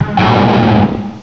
cry_not_terrakion.aif